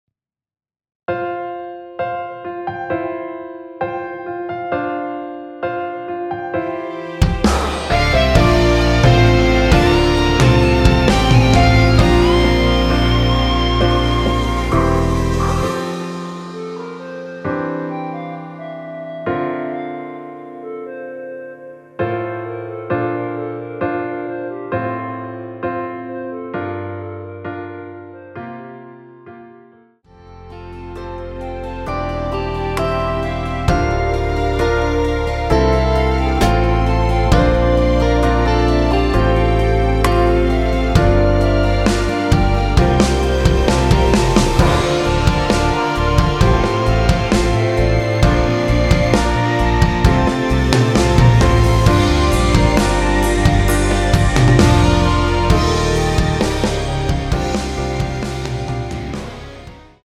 원키에서(+4)올린 (1절앞+후렴)으로 진행되는멜로디 포함된 MR입니다.(미리듣기 확인)
앞부분30초, 뒷부분30초씩 편집해서 올려 드리고 있습니다.
중간에 음이 끈어지고 다시 나오는 이유는